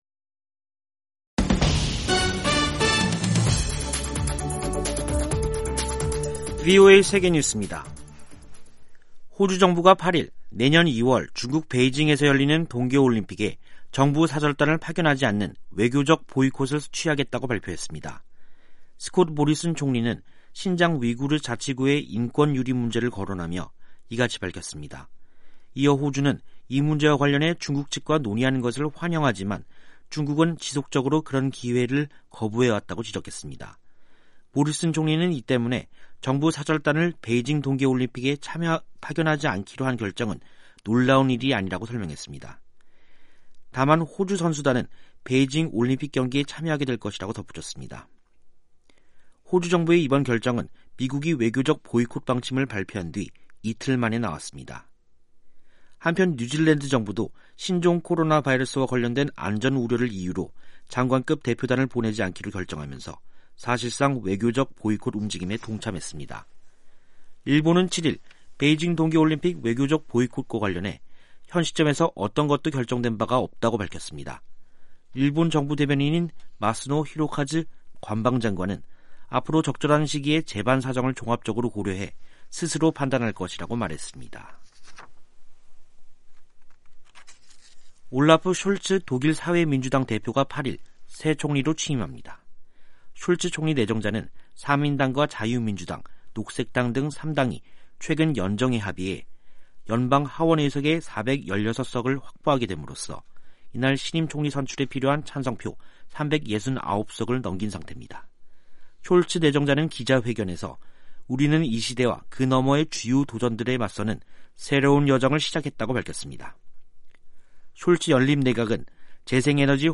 세계 뉴스와 함께 미국의 모든 것을 소개하는 '생방송 여기는 워싱턴입니다', 2021년 12월 8일 저녁 방송입니다. '지구촌 오늘'에서는 미국과 러시아 정상이 화상 회담에서 우크라이나 문제 등을 논의한 소식, '아메리카 나우'에서는 오미크론 변이 확산에 대응해야한다고 보건 당국자들이 촉구한 이야기 전해드립니다.